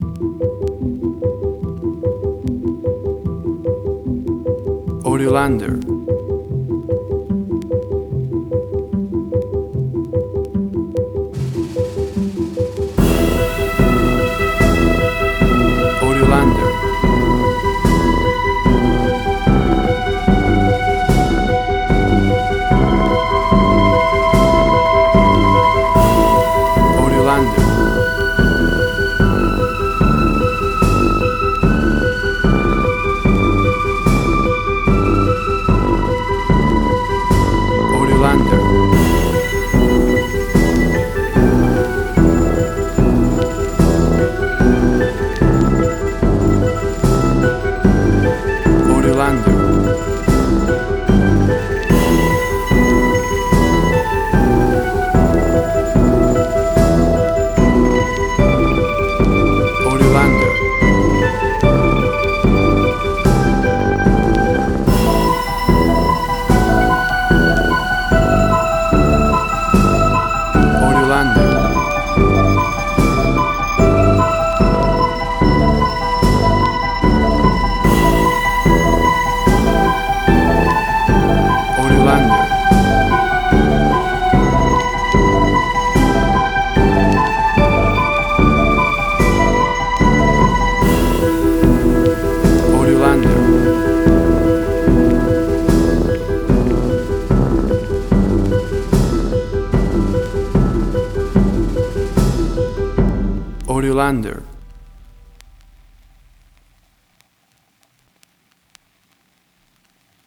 Steampunk Sci-fi,
Tempo (BPM): 74